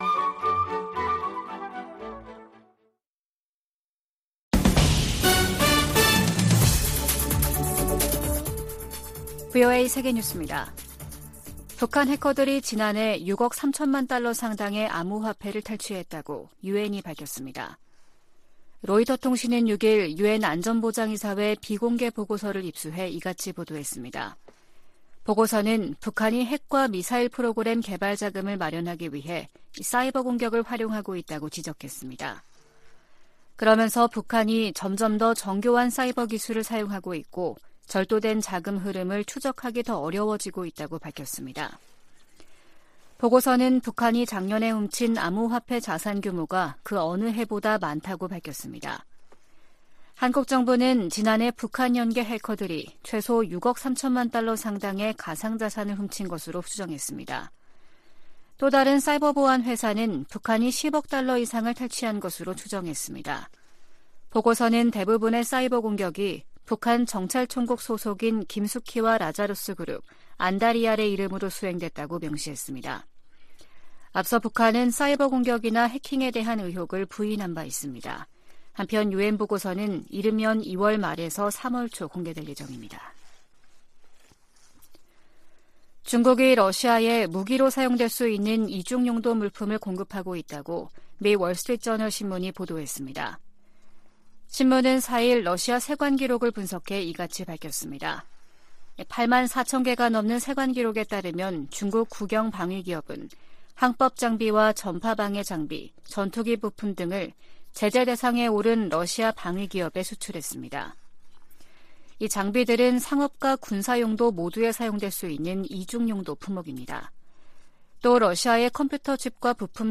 VOA 한국어 아침 뉴스 프로그램 '워싱턴 뉴스 광장' 2023년 2월 8일 방송입니다. 미국 백악관은 미국 상공에 정찰풍선을 띄운 중국의 행동은 용납될 수 없다면서 미중 관계 개선 여부는 중국에 달려 있다고 지적했습니다. 미 국무부는 북한의 열병식 준비 움직임을 늘 지켜보고 있으며 앞으로도 계속 주시할 것이라고 밝혔습니다.